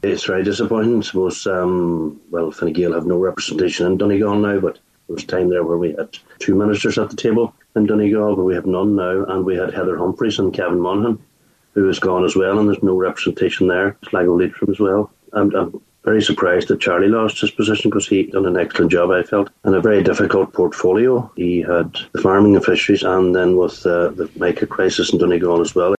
On today’s Nine til Noon Show, Cllr Harley said Ulster now has no senior ministry, and from the Fine Gael perspective, the situation is even worse…….